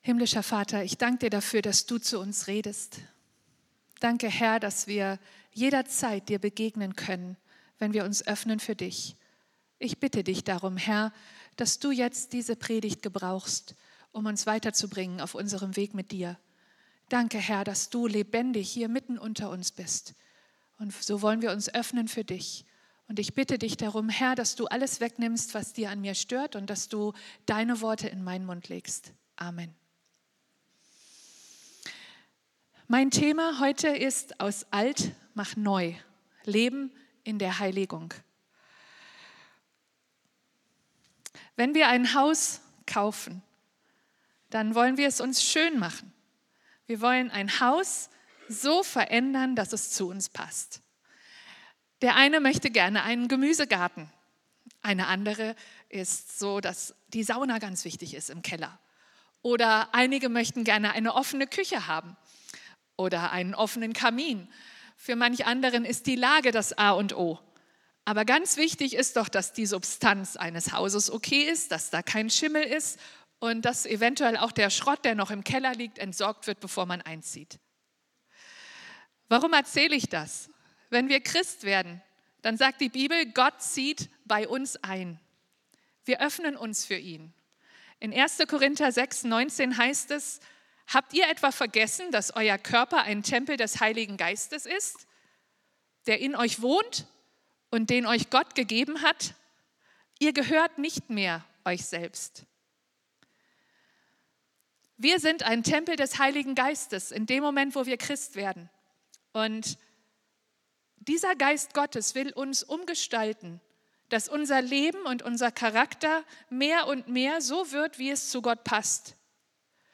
Gottesdienst International